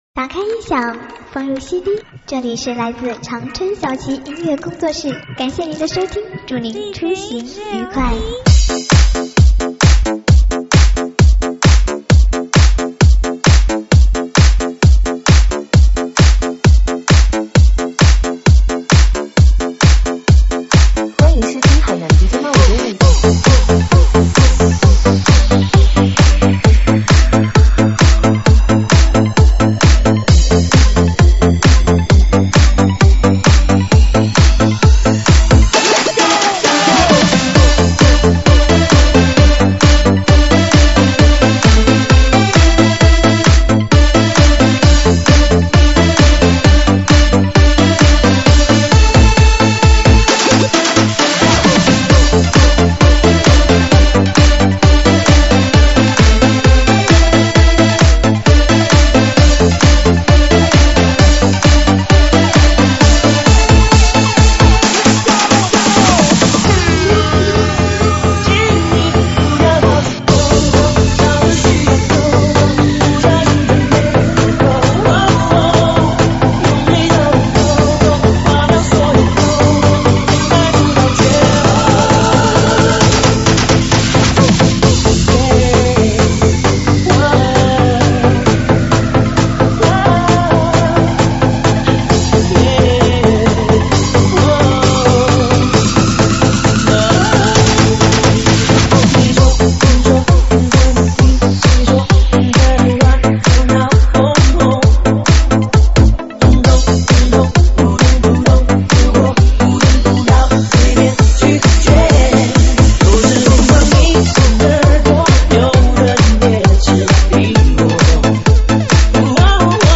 电子Electro